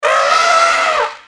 大象 | 健康成长
elephant-sound.mp3